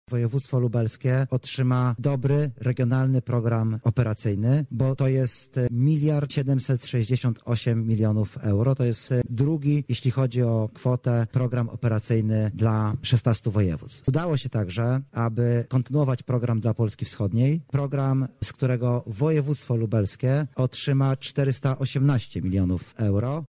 O środkach wyznaczonych dla województwa lubelskiego mówi wiceminister aktywów państwowych Artur Soboń.